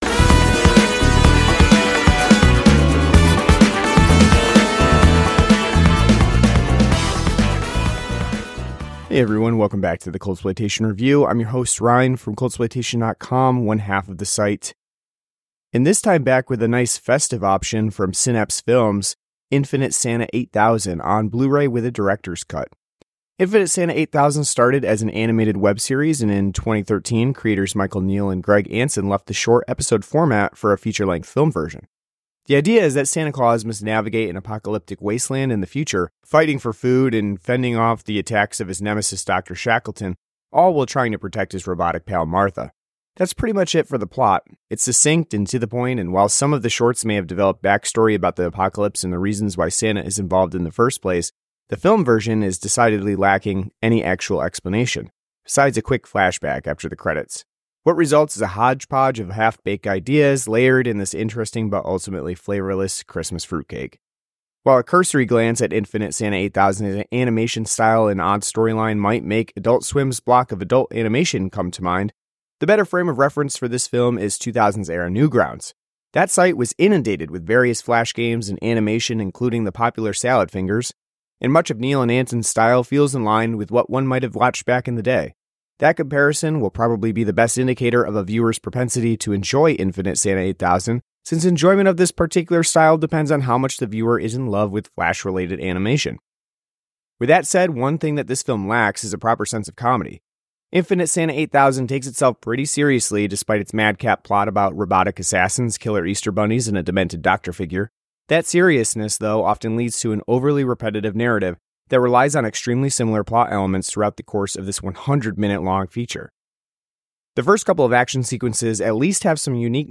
An audio review of Infinite Santa 8000 on Blu-ray from Synapse Films.